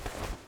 Player Character SFX
crouch2.wav